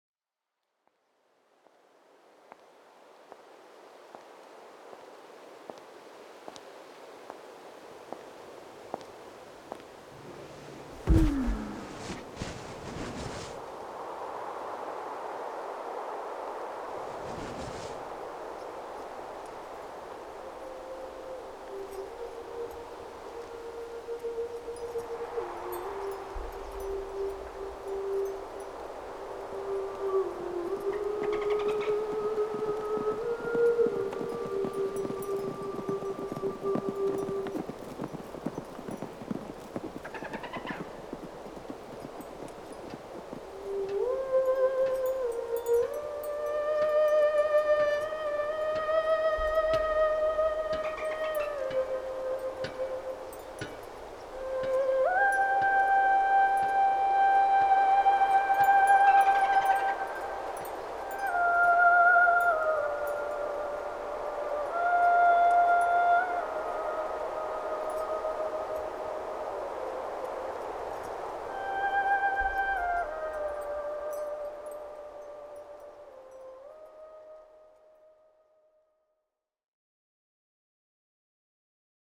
Soundscape
soundscape.wav